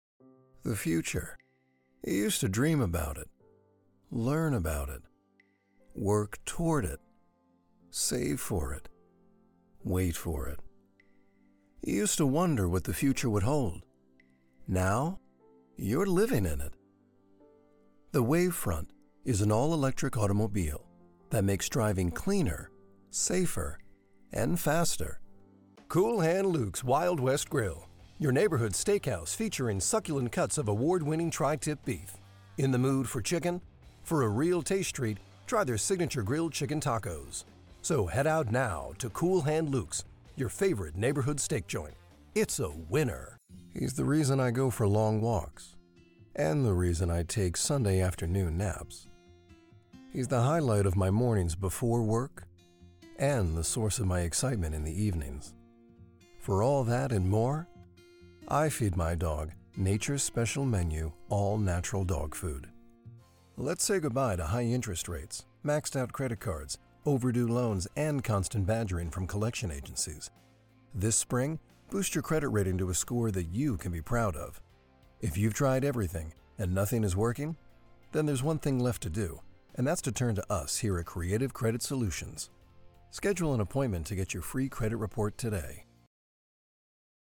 Male
Within the bass and baritone range, my voice can exhibit a casual guy-next-store vibe to authoritative Voice-of-God style to upbeat and energized.
Radio Commercials
Advertisement Demo (4 Spots)